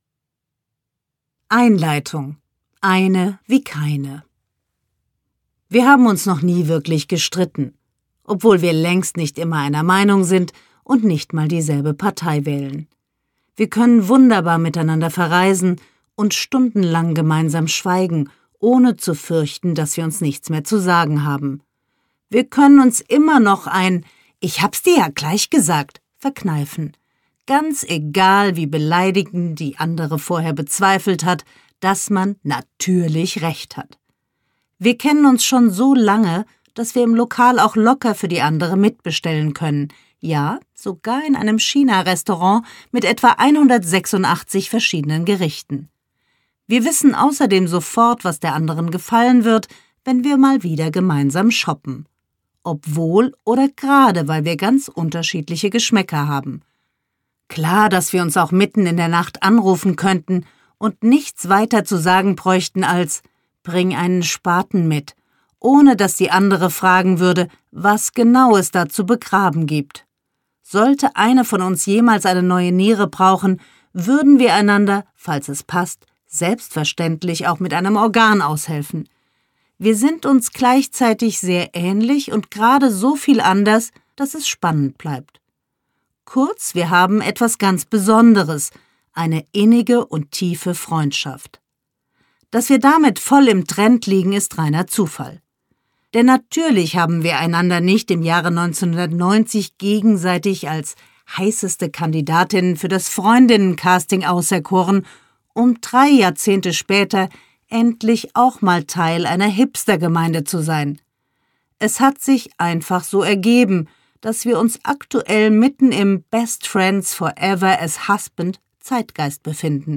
Hörbuch: Wenn ich Dich nicht hätte!
Freundinnen, eine geniale Liebe Susanne Fröhlich , Constanze Kleis (Autoren) Susanne Fröhlich (Sprecher) Audio-CD 2020 | 1.